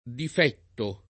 difettare v.; difetto [ dif $ tto ]